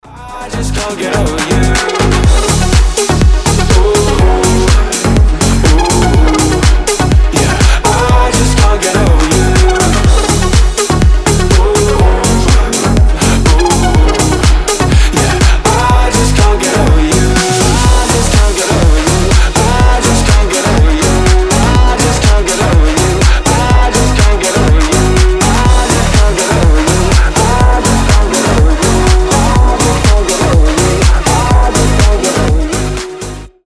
Танцевальные рингтоны
Клубные рингтоны